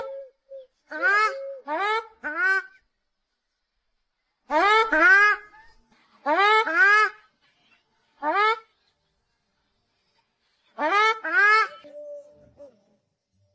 两只赤麻鸭一起鸣叫